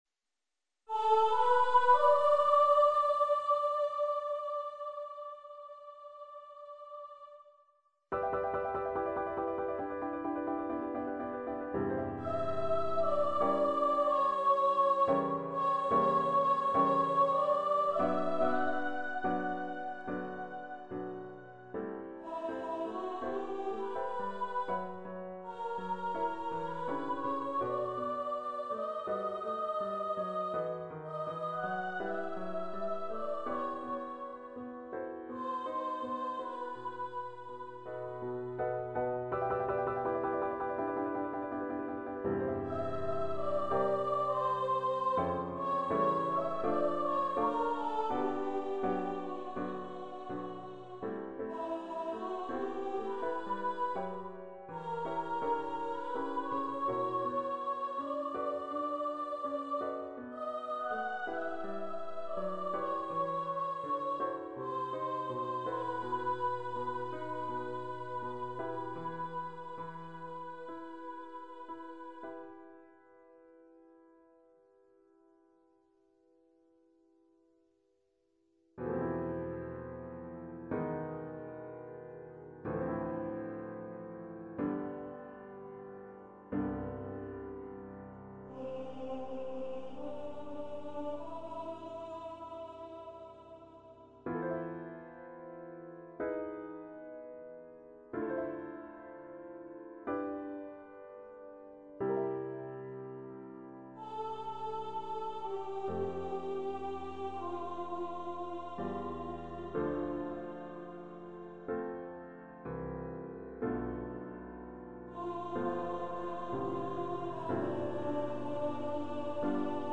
Voice and Piano
Composer's Demo